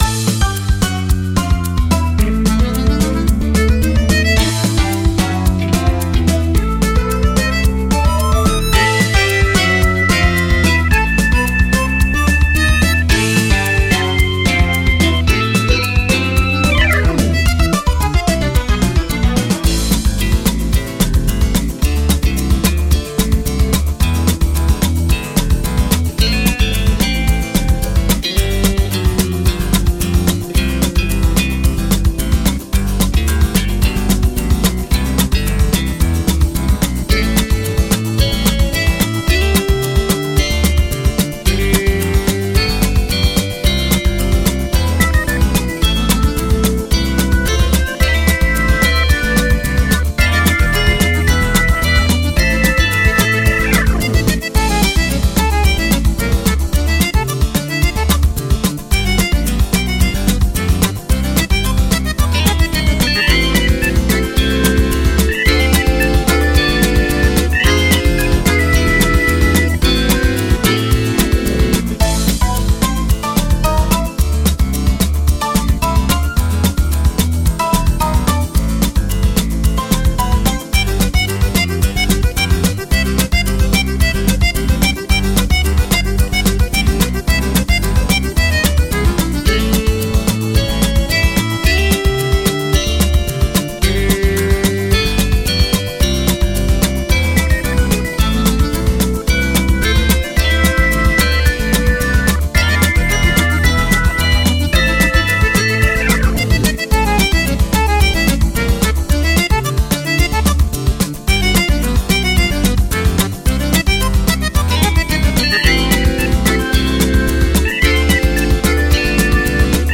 ID: 420035 Актёр; Певец; Россия
Вокал исполнителя звучит в унисон со звучанием его гитар. Аккомпонимент Гитар - перебор струн Его Души.